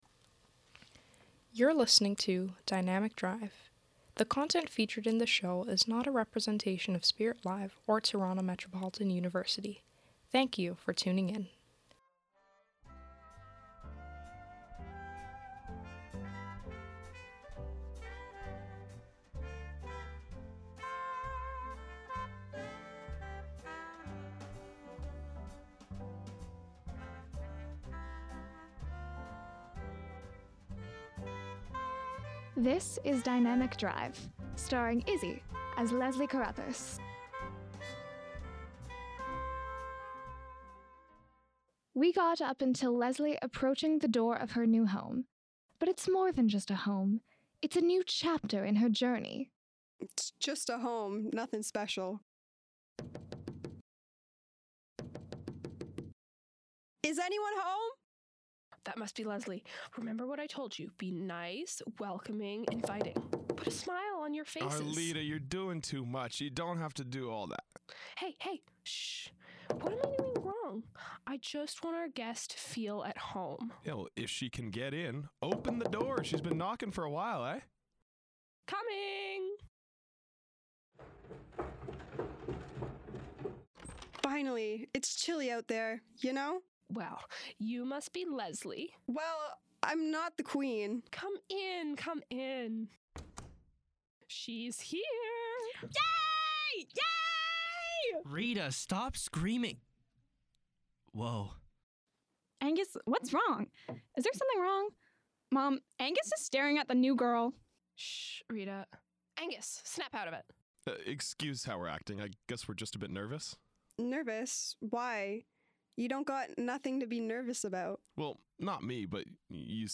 Dynamic Drive is a radio comedy series centred around Leslie Caruthers, a New York exchange student who moves to Toronto with the Bannatyne family.